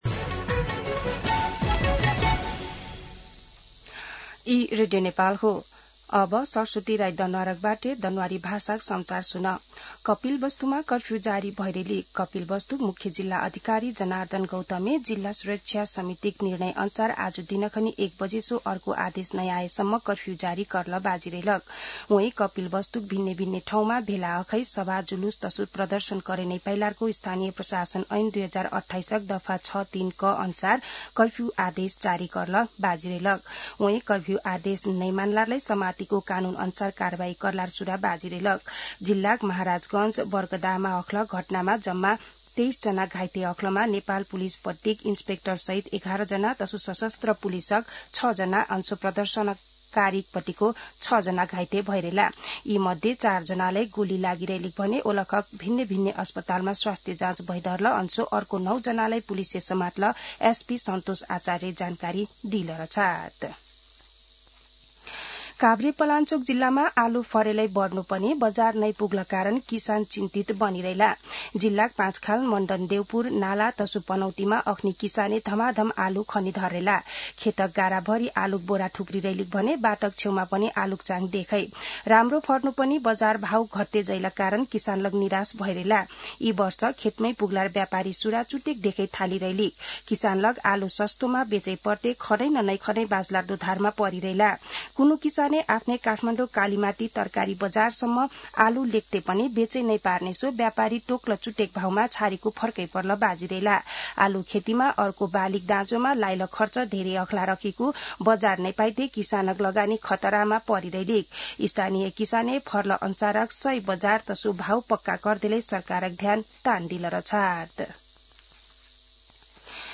An online outlet of Nepal's national radio broadcaster
दनुवार भाषामा समाचार : ८ चैत , २०८२
Danuwar-News-1.mp3